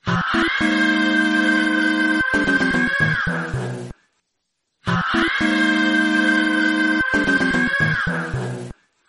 italian immigrant song